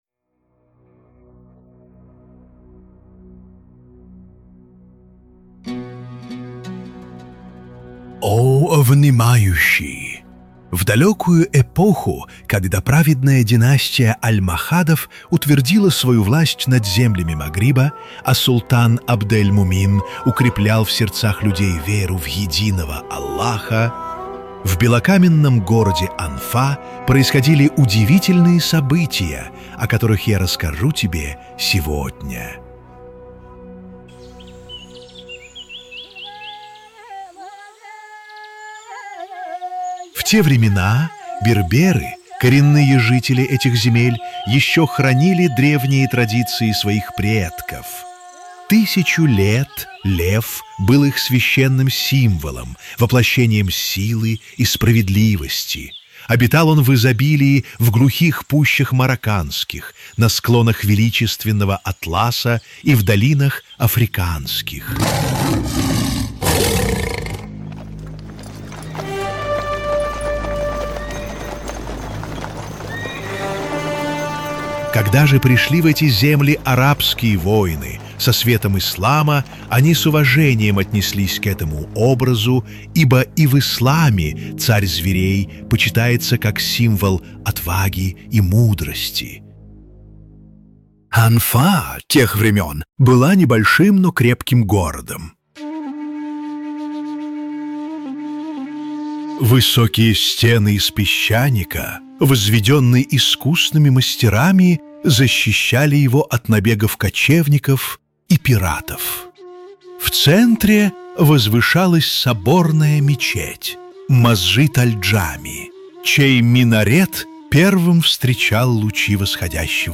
🎧 Аудиоверсия моей сказки о Сиди Бельюте 📖 Я написал эту сказку, вдохновлённый историей старой Касабланки, её святым покровителем и тем, как легенды живут среди нас - в названиях улиц, в мечтах, в традициях.